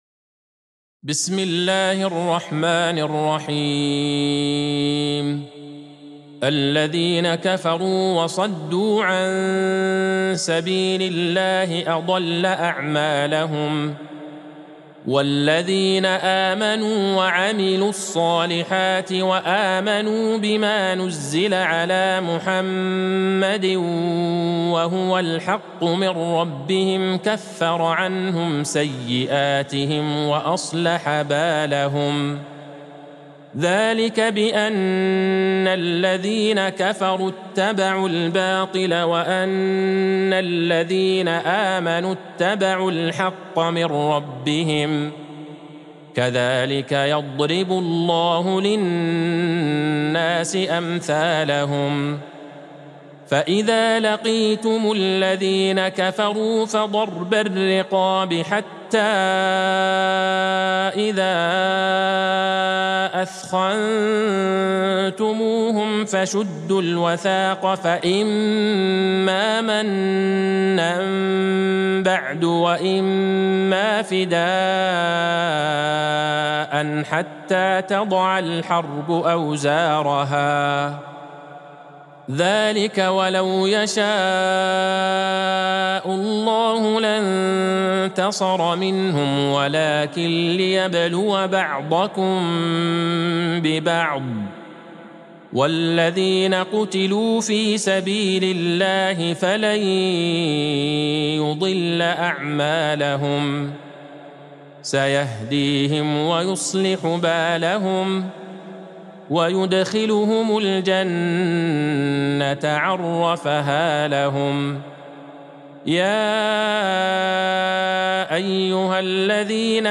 سورة محمد Surat Muhammad | مصحف المقارئ القرآنية > الختمة المرتلة ( مصحف المقارئ القرآنية) للشيخ عبدالله البعيجان > المصحف - تلاوات الحرمين